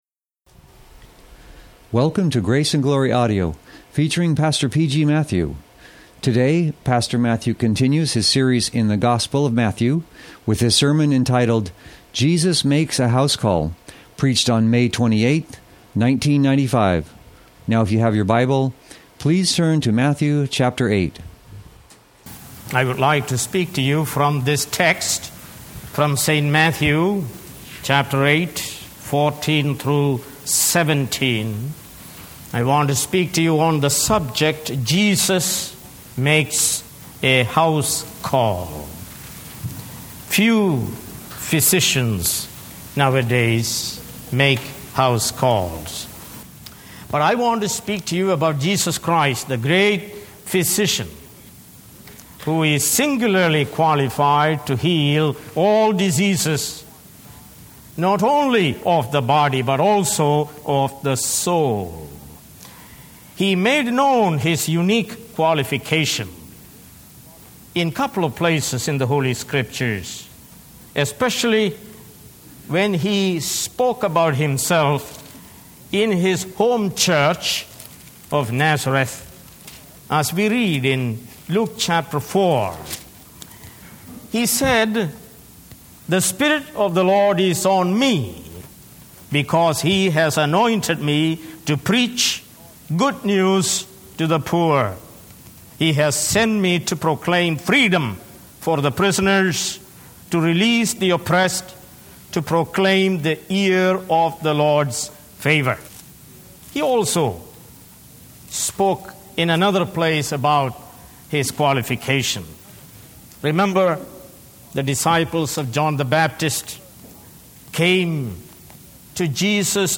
Sermons | Grace Valley Christian Center | Page 5